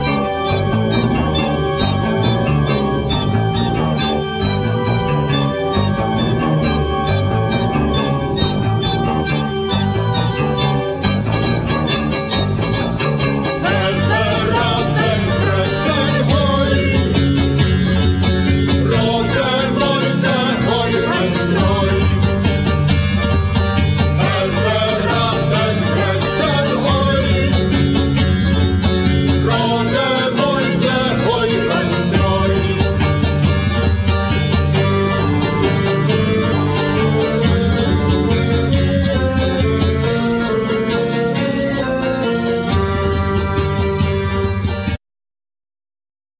Accordion,Guitar,Vocals
Clarinet,Guitar,Vocals
Drums
Baritone&Tennor Sax